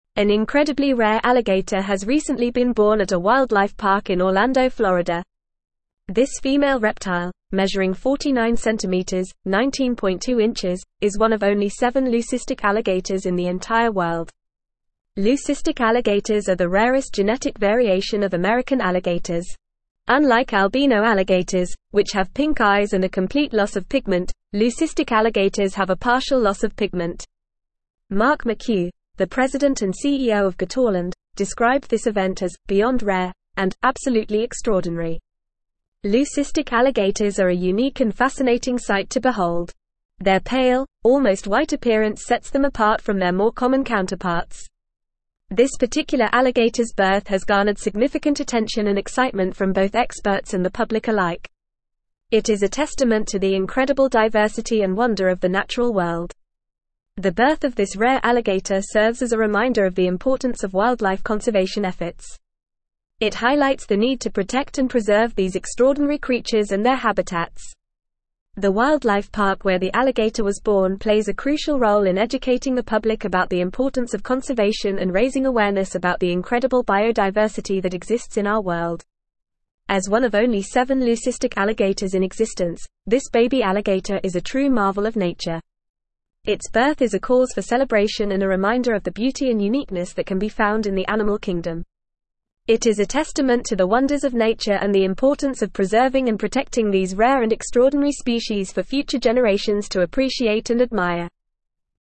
Fast
English-Newsroom-Advanced-FAST-Reading-Rare-White-Alligator-Born-at-Orlando-Wildlife-Park.mp3